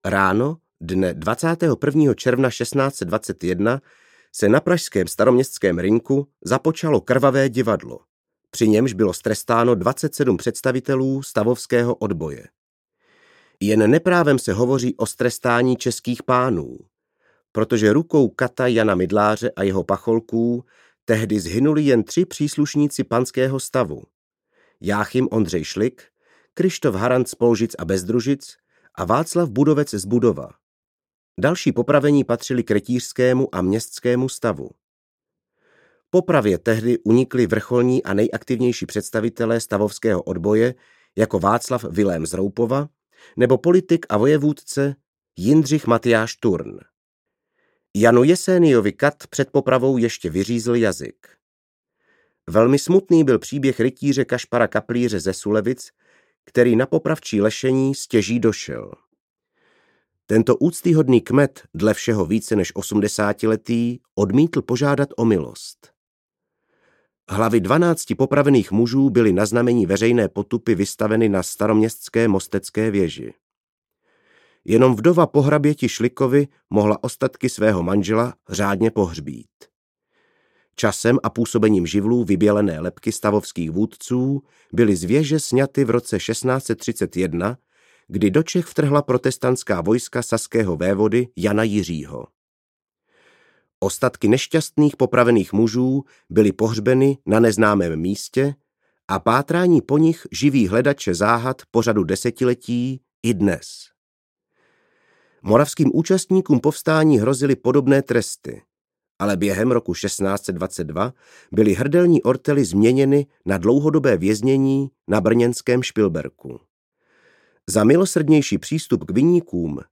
Temno: Stručná historie audiokniha
Ukázka z knihy